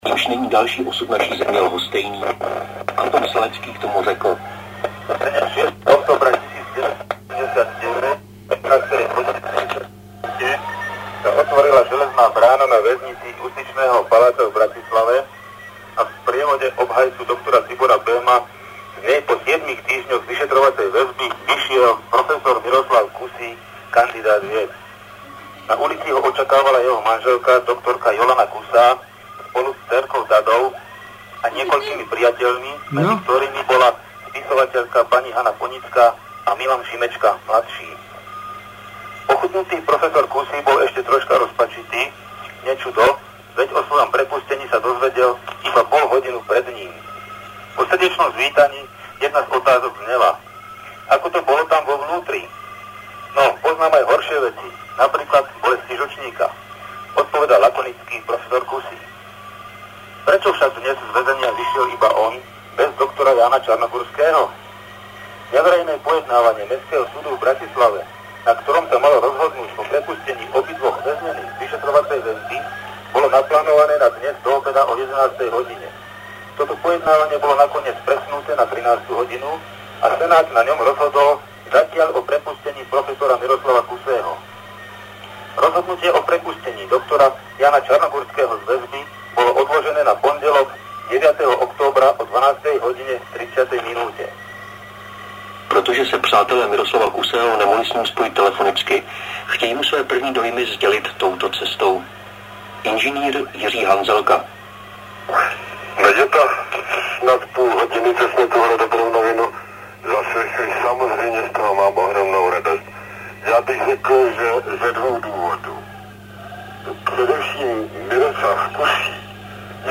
Z vysielania Hlasu Ameriky a Rádia Slobodná Európa  (august – november 1989)    August 1989